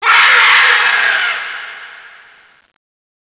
spider_death1.wav